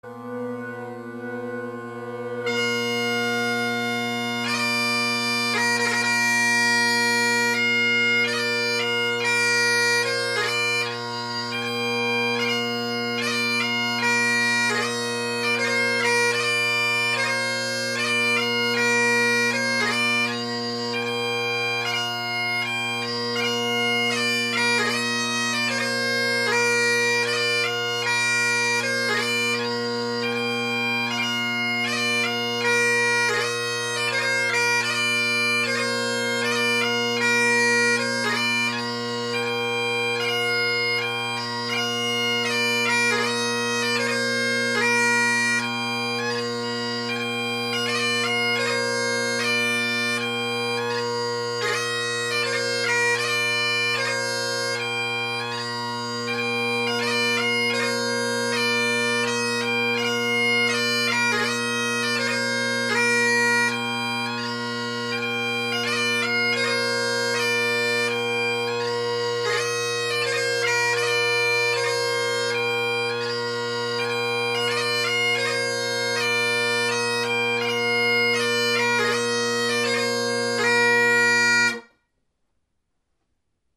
Drone Sounds of the GHB, Great Highland Bagpipe Solo
I play the same tune in each recording; a new composition of mine.
Cane tongued Ackland bass drone reed